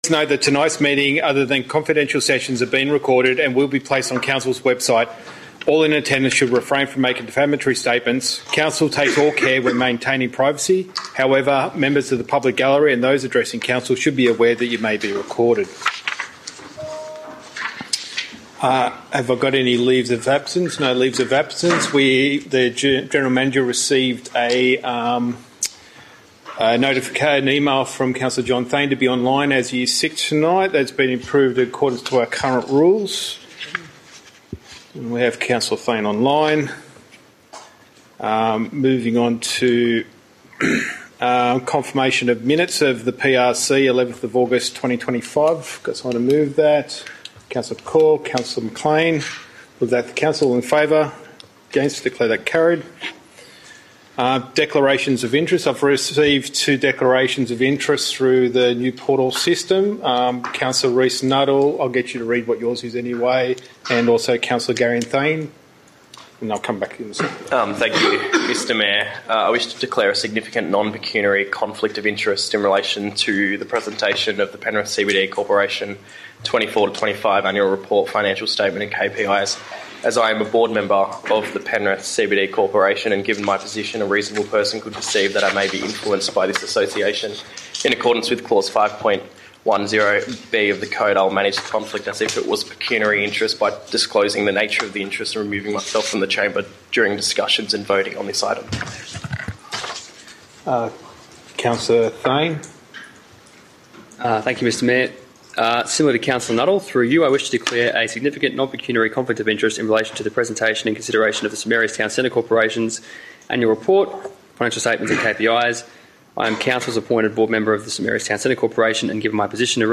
Archive of Penrith City Council Meetings.